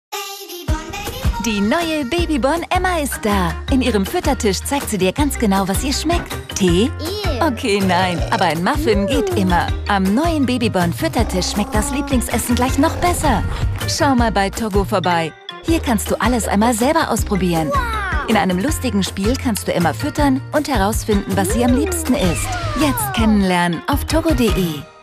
hell, fein, zart, sehr variabel
Mittel plus (35-65)
Commercial (Werbung)